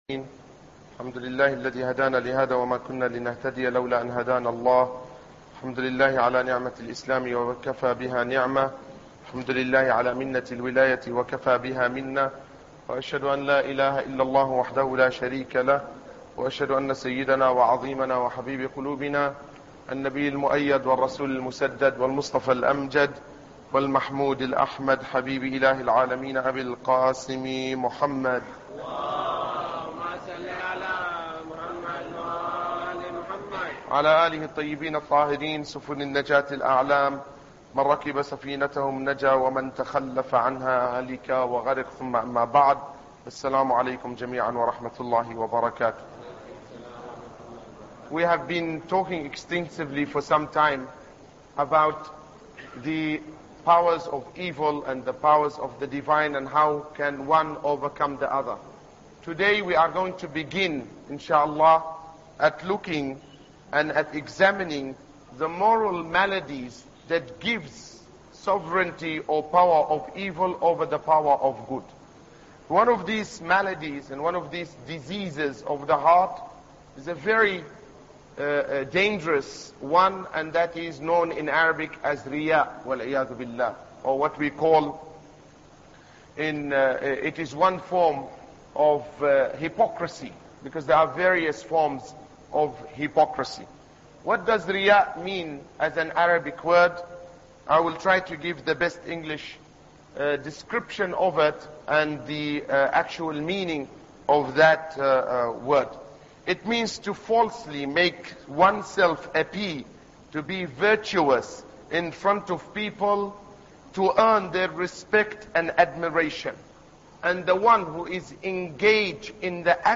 Ramadan Lecture 8